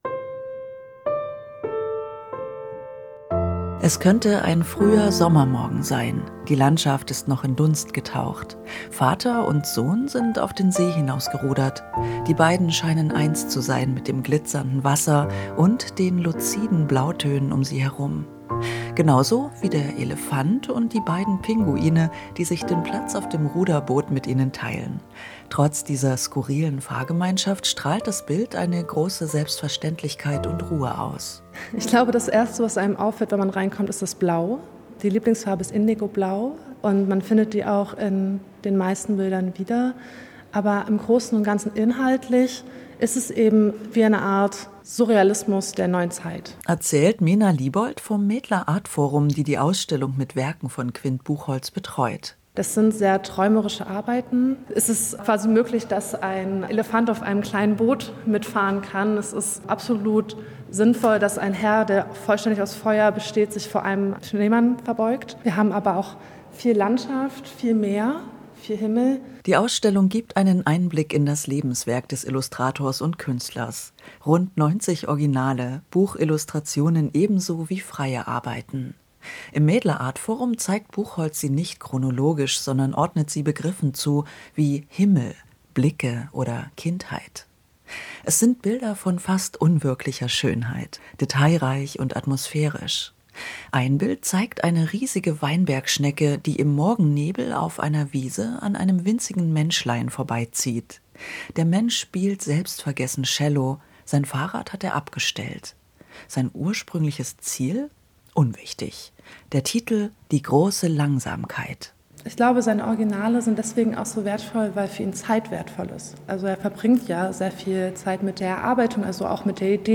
Radiobeitrag MDR Kultur